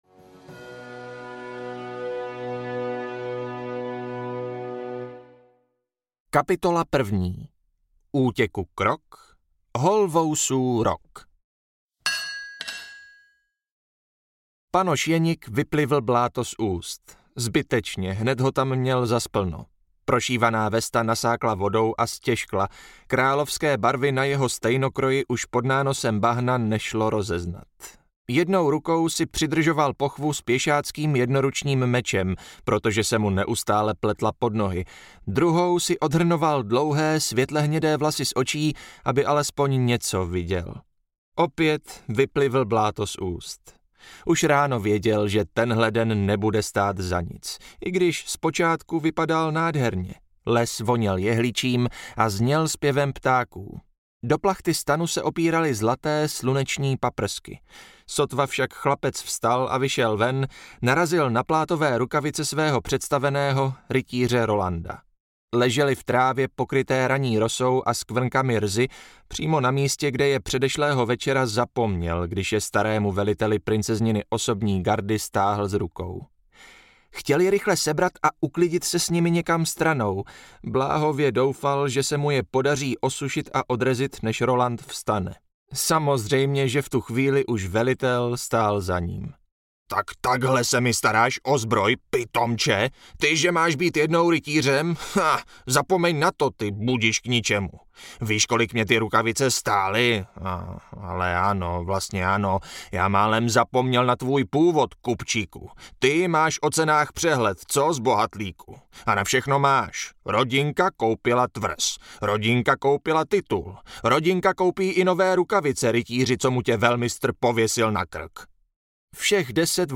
Křívé ostří audiokniha
Ukázka z knihy
• InterpretLibor Böhm